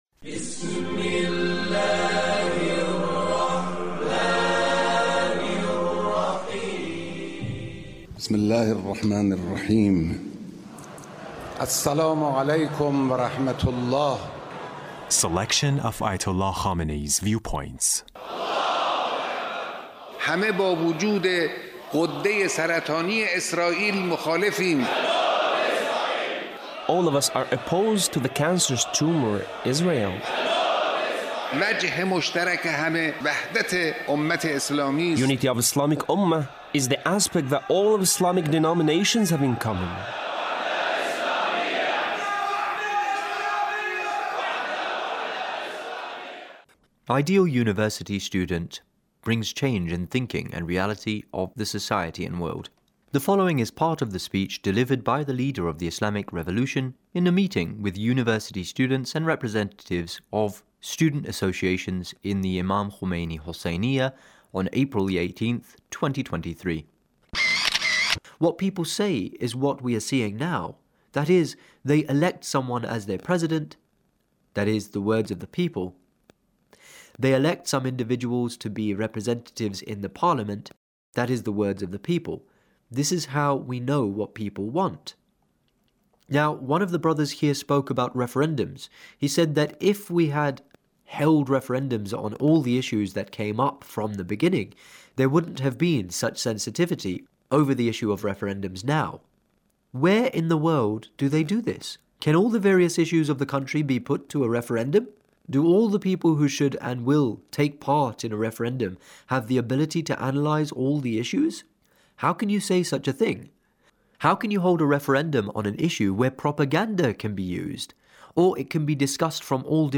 Leader's Speech with University Student